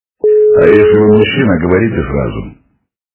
Мужской голос - Говорите сразу Звук Звуки Голос чоловіка - Говорите сразу
» Звуки » Люди фразы » Мужской голос - Говорите сразу
При прослушивании Мужской голос - Говорите сразу качество понижено и присутствуют гудки.